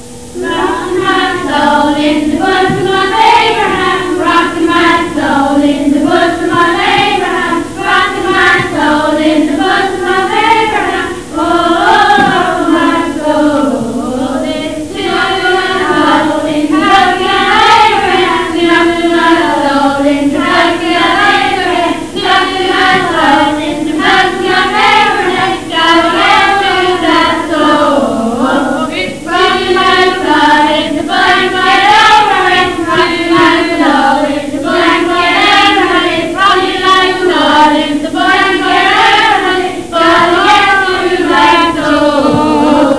Click on any of the following songs to hear us singing: